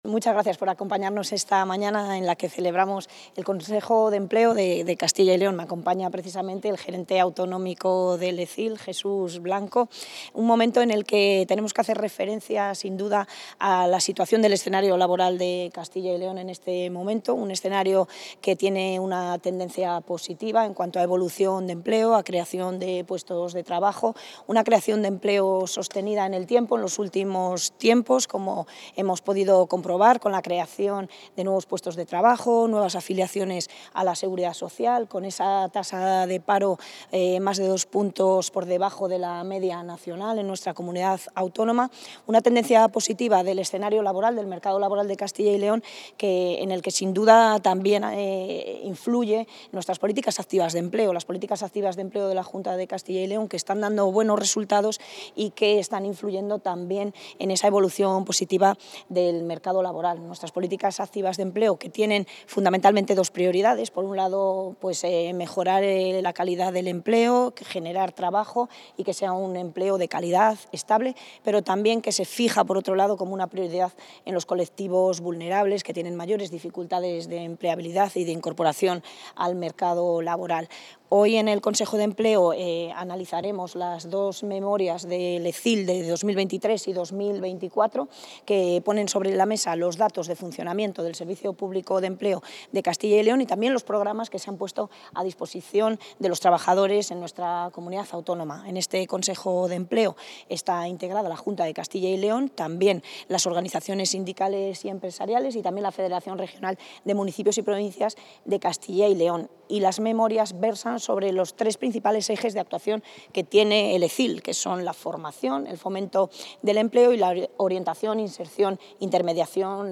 Intervención de la consejera.
La consejera de Industria, Comercio y Empleo, Leticia García, ha presidido esta mañana el pleno del Consejo General de Empleo, en el Edificio de Soluciones Empresariales de Arroyo de la Encomienda, en Valladolid.